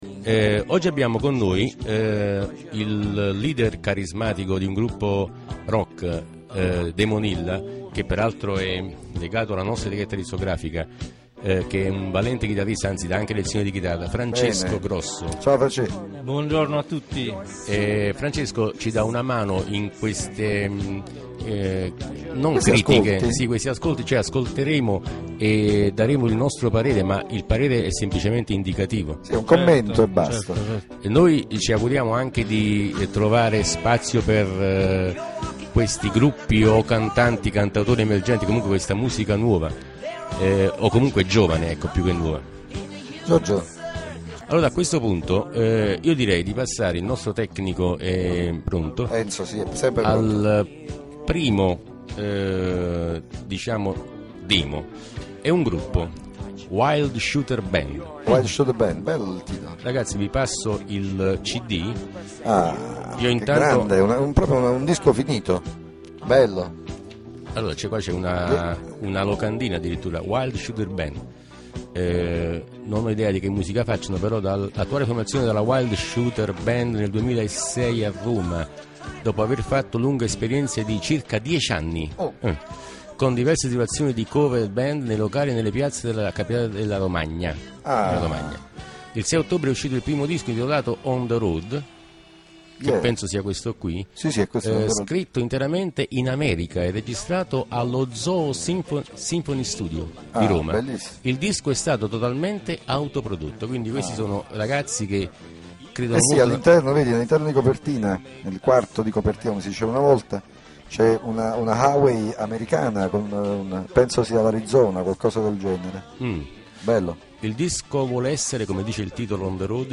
INTERVIEWS AUDIO